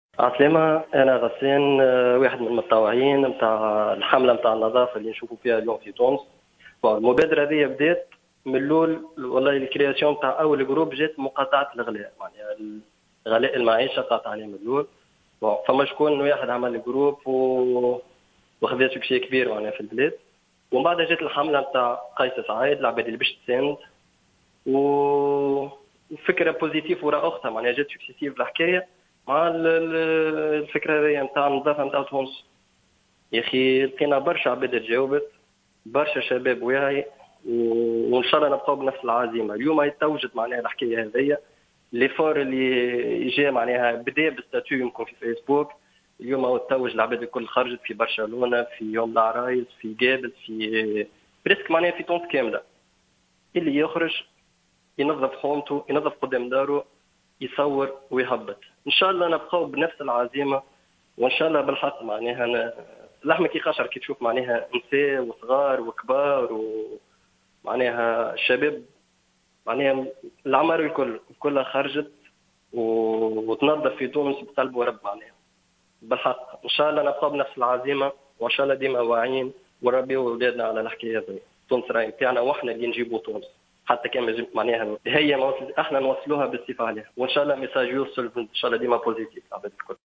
أحد المشاركين في حملة النظافة